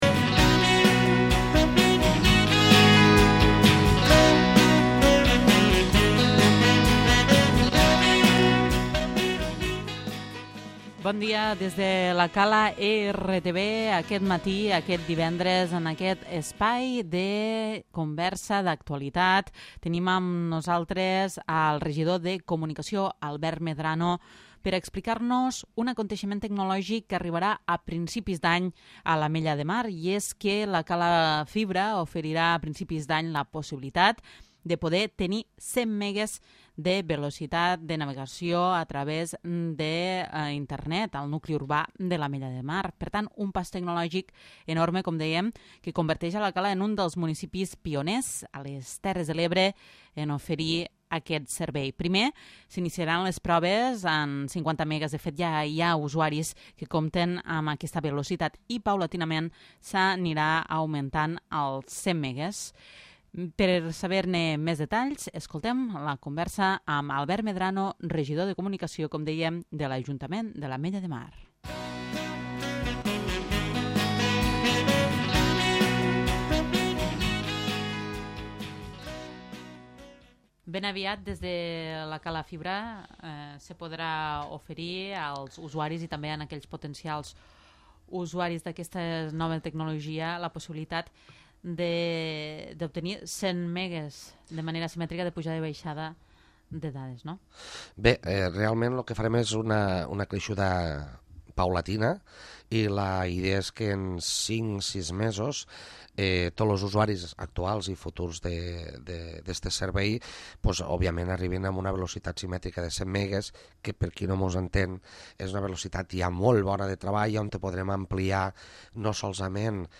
L'Entrevista
El regidor de comunicació de l'Ajuntament de l'Ametlla de Mar, Albert Medrano, ens parla del servei de 100 megues que podrà oferir la Cala Fibra a inicis del 2014.